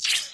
terumet_squish_step.2.ogg